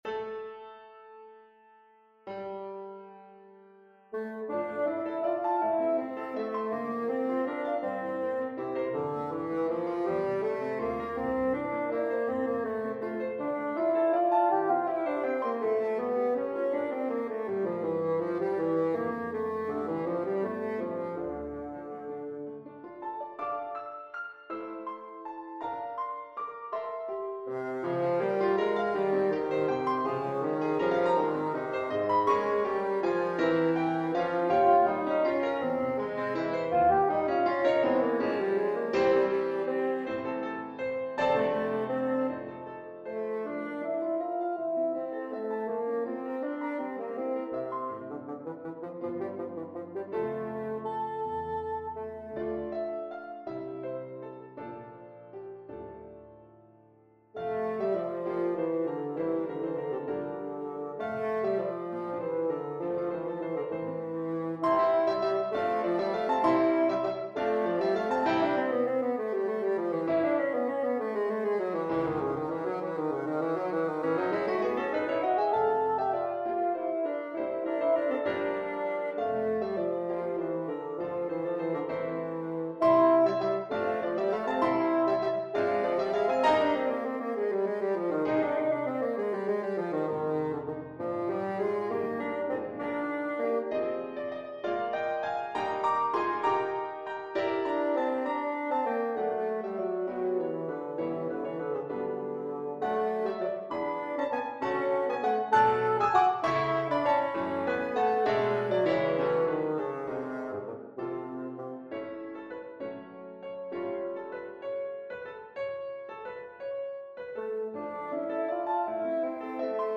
6/8 (View more 6/8 Music)
Bassoon  (View more Intermediate Bassoon Music)
Classical (View more Classical Bassoon Music)